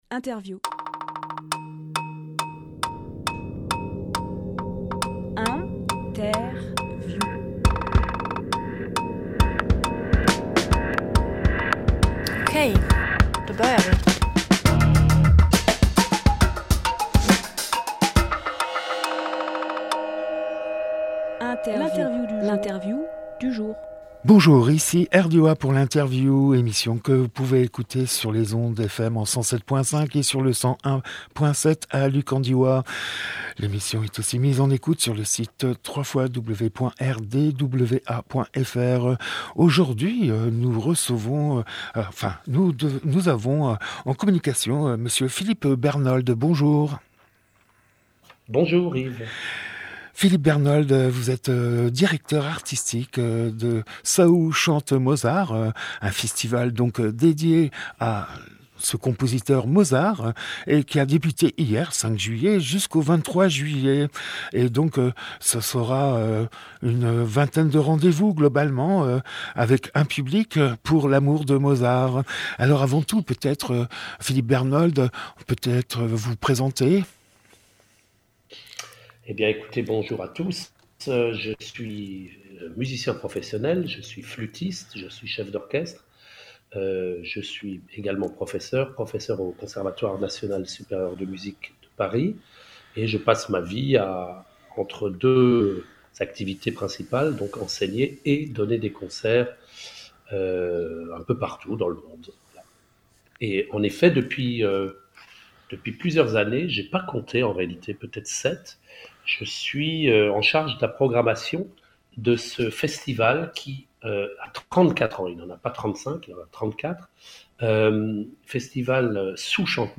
Emission - Interview Saoù chante Mozart et le Requiem à Die Publié le 6 juillet 2023 Partager sur…
06.07.23 Lieu : Studio RDWA Durée